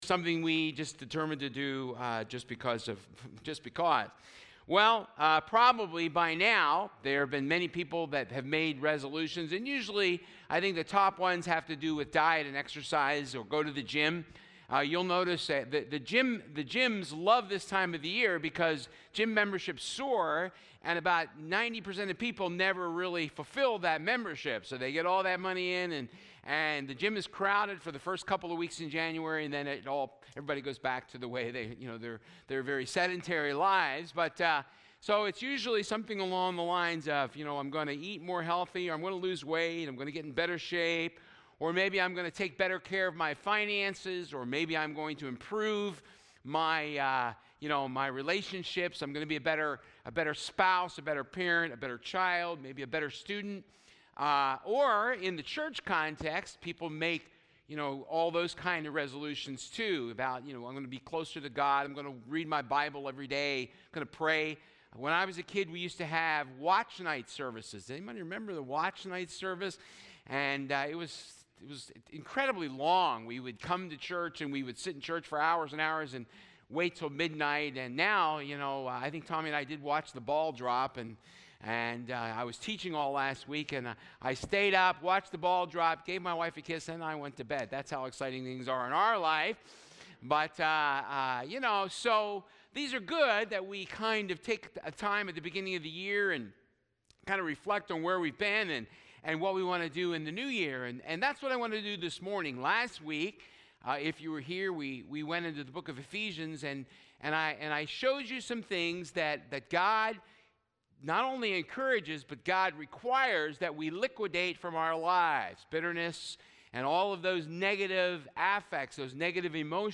Misc Passage: Romans 12:1 Service Type: Sunday Service Compelling resolutions for 2016 « December 6